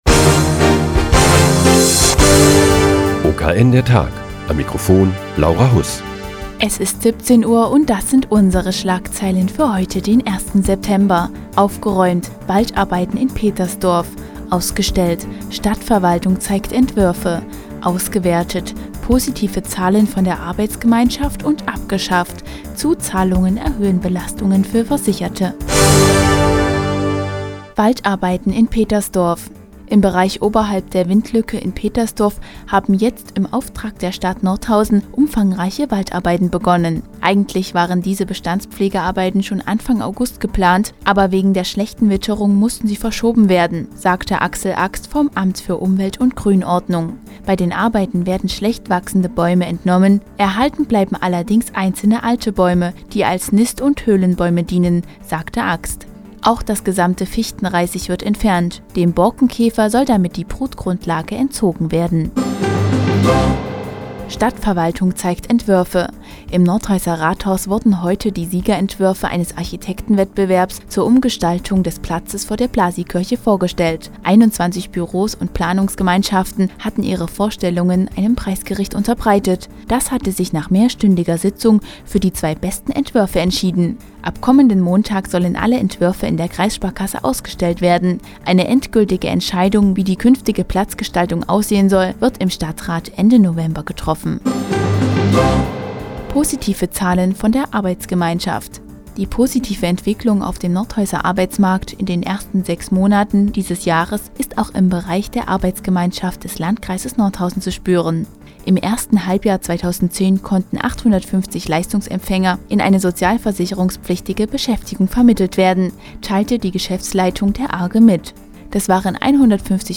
Die tägliche Nachrichtensendung des OKN ist nun auch in der nnz zu hören. Heute geht es um Waldarbeiten in Petersdorf und die Vorstellung der Siegerentwürfe des Blasiikirchplatz- Wettbewerbs.